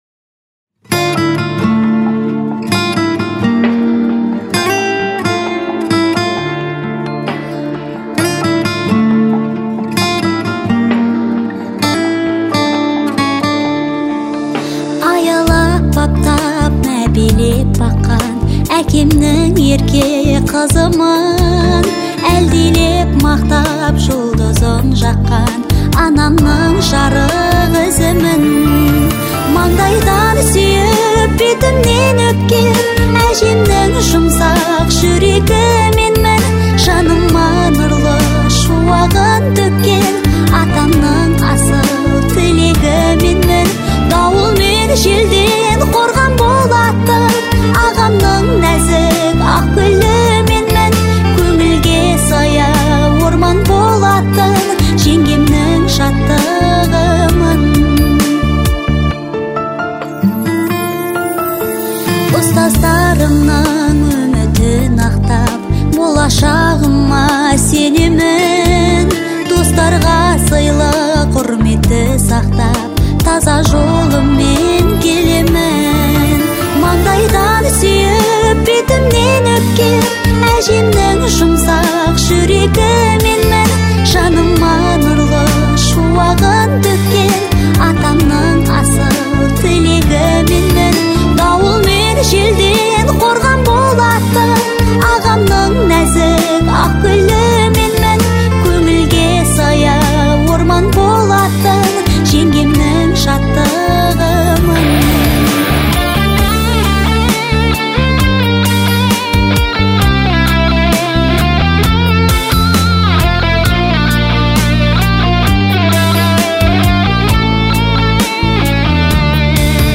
это яркая и мелодичная песня в жанре поп